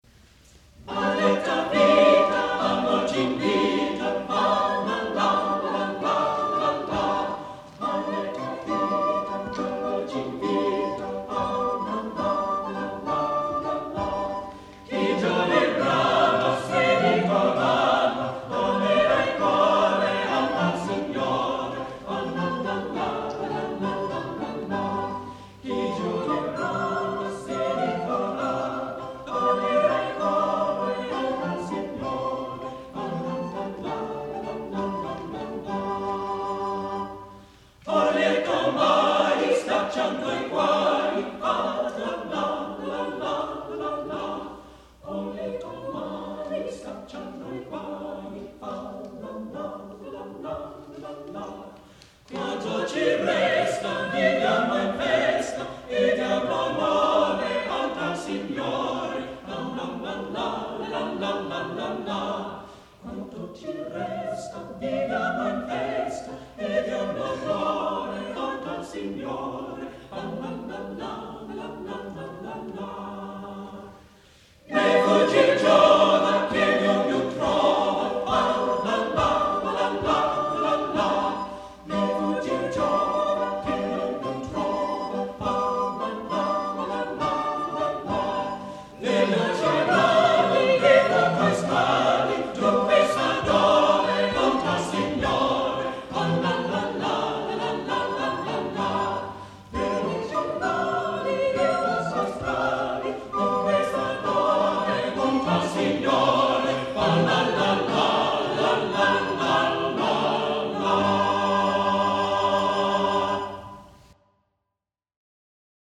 Although he was a church musician all his life he is best known for his ‘balletti’, which are light, direct, simple dance-songs that use nonsense syllables like “Fa-la-la”.